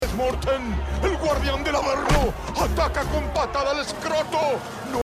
more shots to the scrotum.
patada-al-escroto.mp3